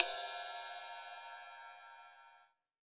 PPING RIDE.wav